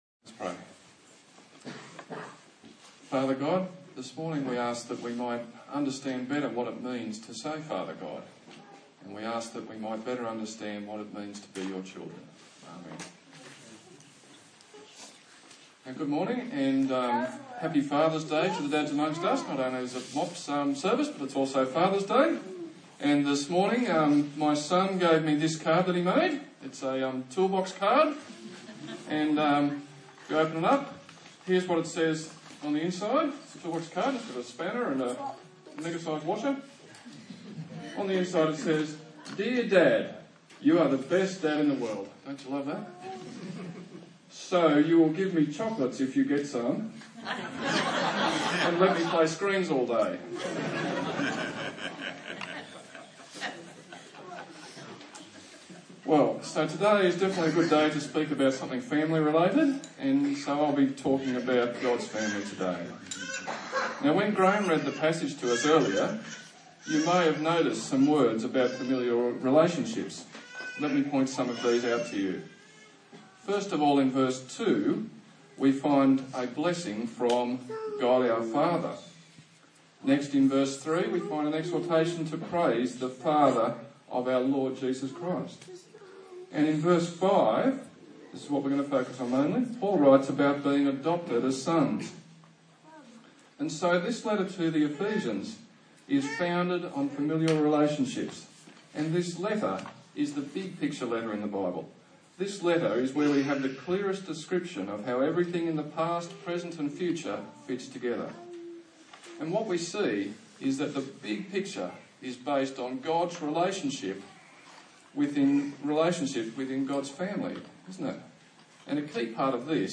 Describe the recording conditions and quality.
Service Type: Sunday Morning A sermon on the book of Ephesians